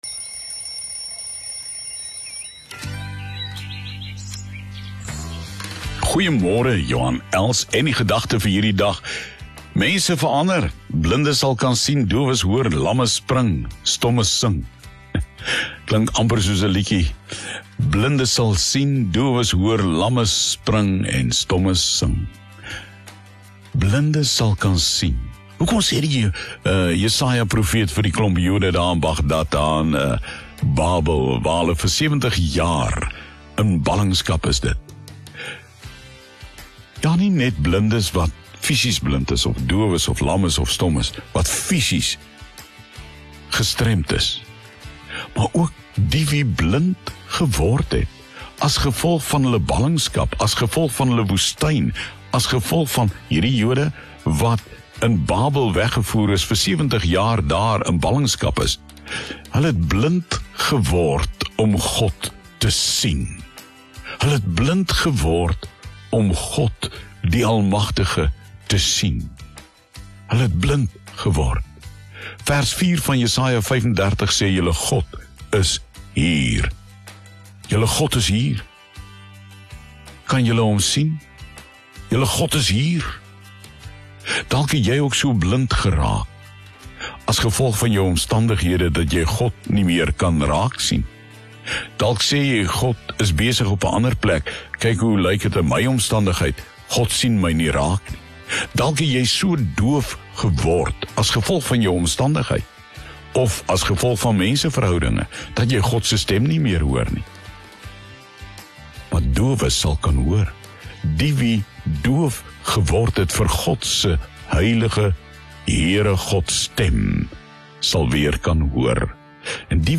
soos gehoor op OFM op 13 Desember 2021.